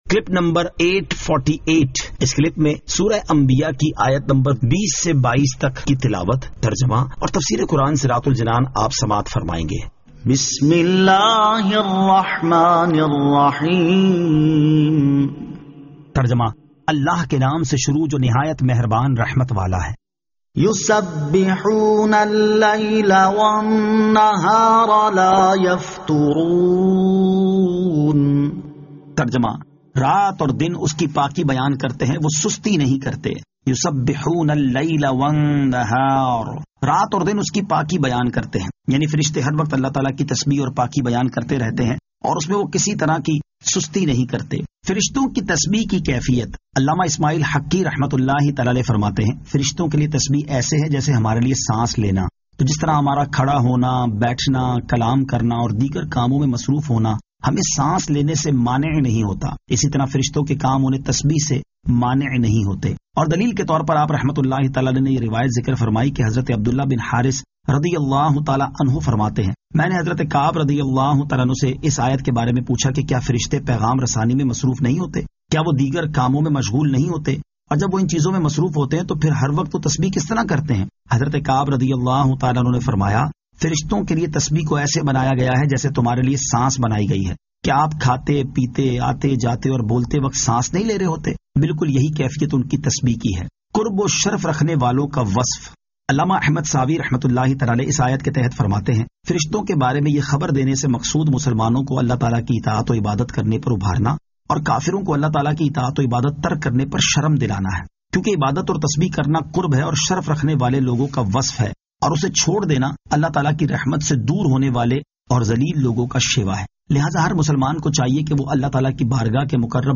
Surah Al-Anbiya 20 To 22 Tilawat , Tarjama , Tafseer